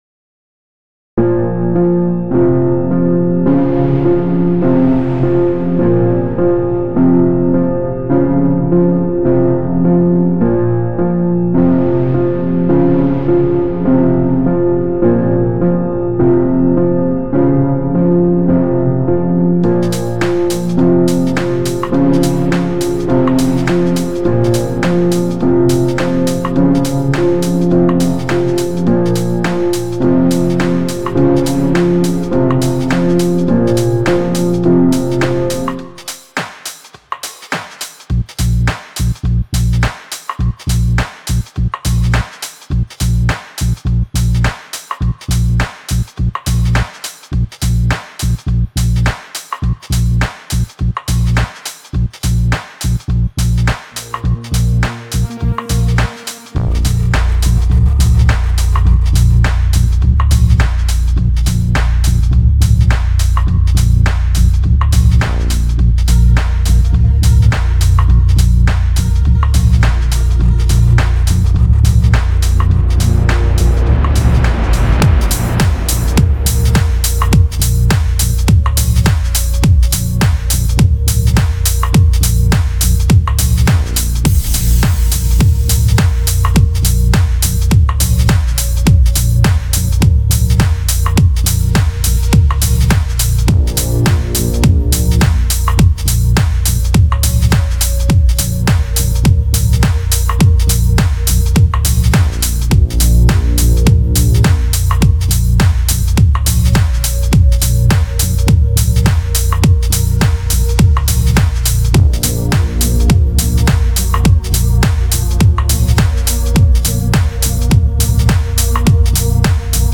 Die von mir erstellte elektronische Musik.